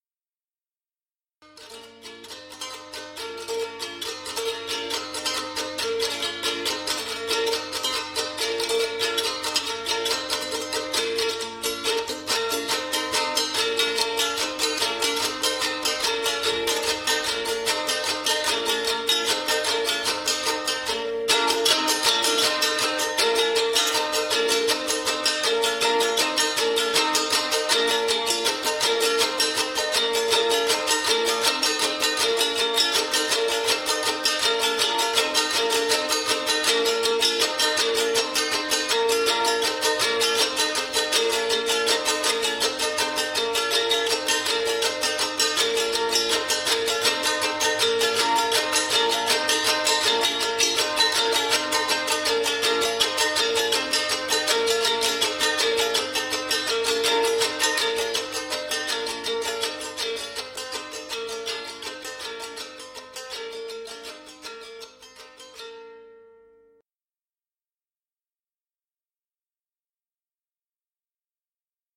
Прослушивание аудиозаписей игры на гуслях в исп.
gusli.mp3